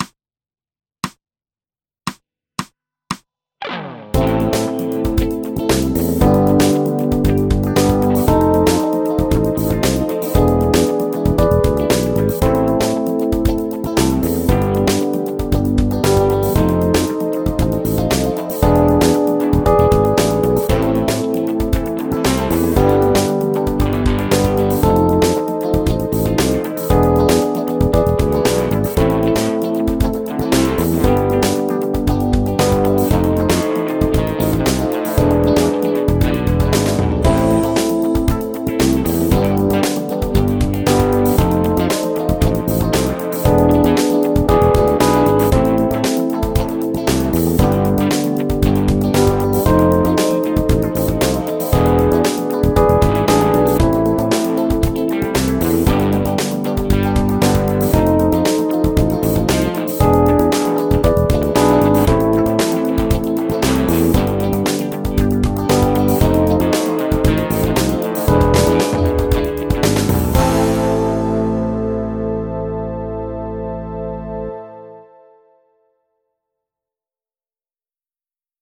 ハーモニック・マイナー・パーフェクト5thビロウ ギタースケールハンドブック -島村楽器